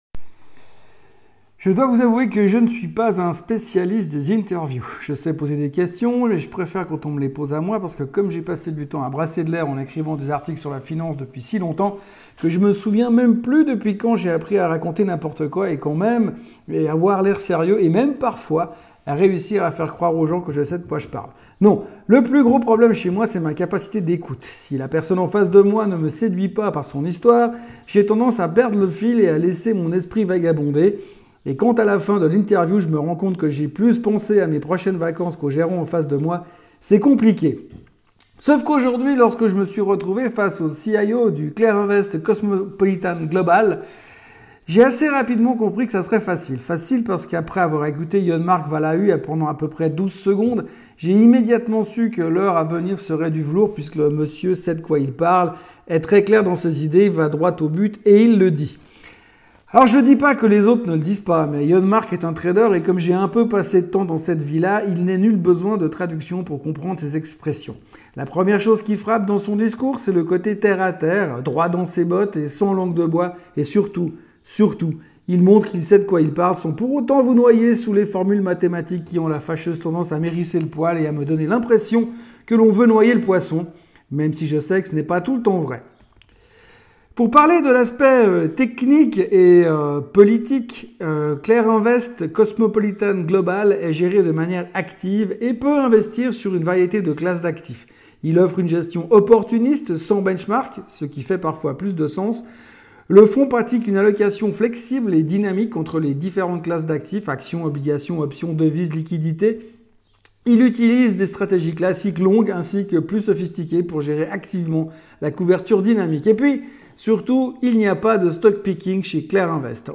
La version AUDIO de l’Interview :